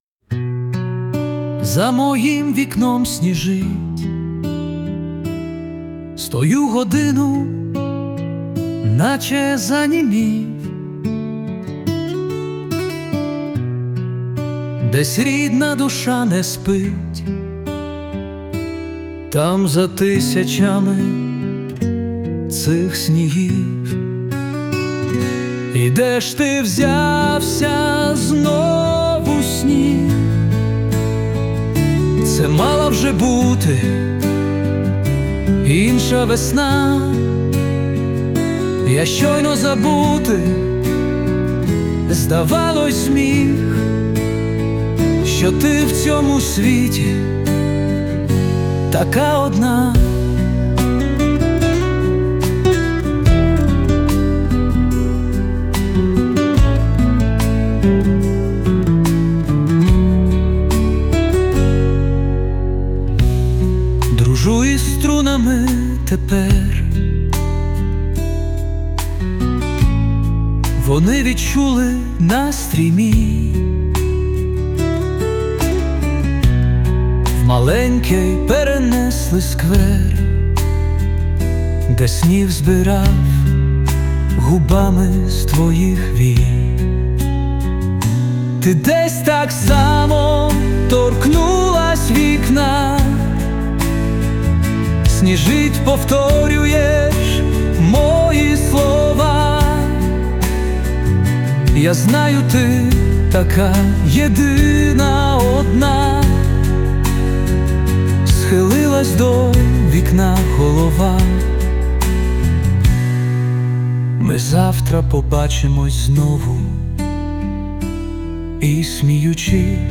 Присутня підтримка SUNO
СТИЛЬОВІ ЖАНРИ: Ліричний